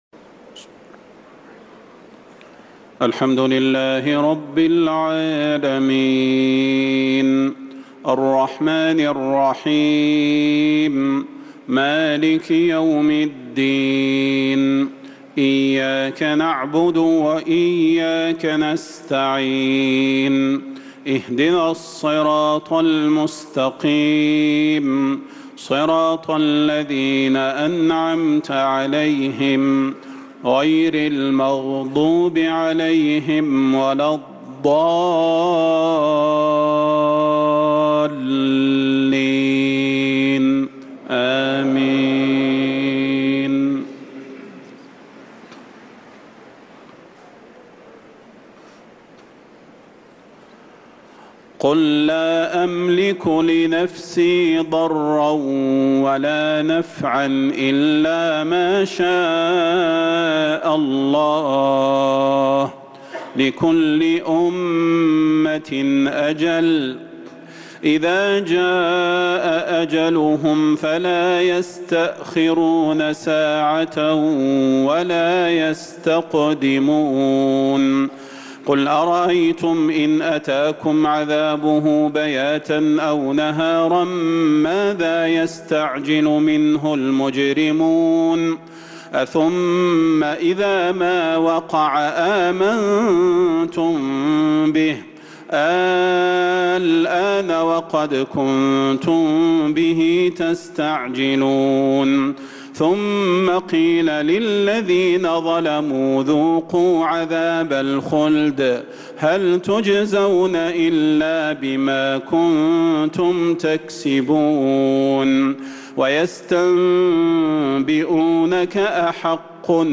إصدار جميع تلاوات الشيخ صلاح البدير في شهر محرم - صفر- ربيع الأول - الآخر 1446هـ > سلسلة الإصدارات القرآنية الشهرية للشيخ صلاح البدير > الإصدارات الشهرية لتلاوات الحرم النبوي 🕌 ( مميز ) > المزيد - تلاوات الحرمين